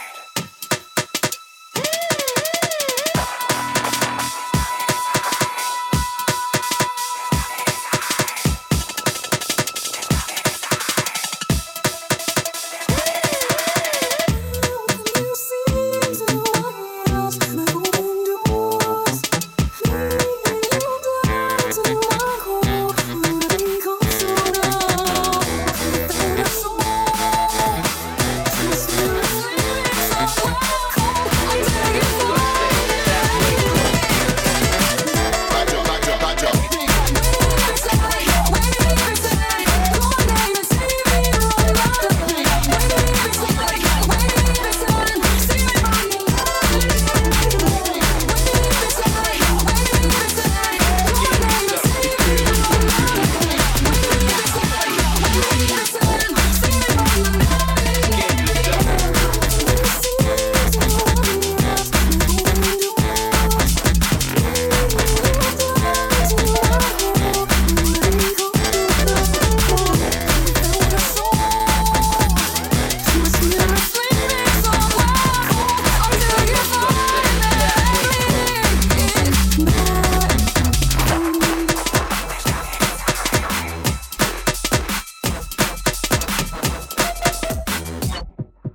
just rough drafts of musical blends